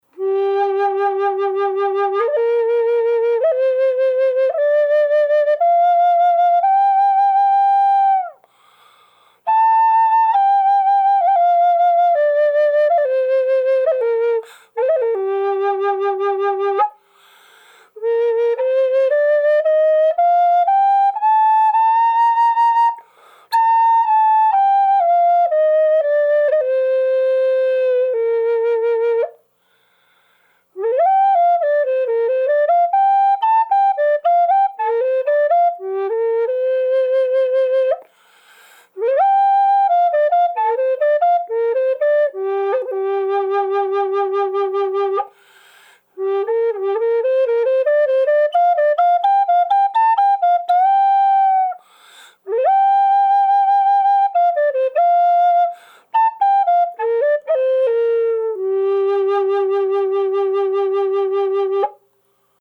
• Burly Tiger Maple Gm
The key is G-minor, which I like to think of as a "happy" key.
Natural "dry" sound of this flute with no enhancement
Tiger_Burl_G_Dry.mp3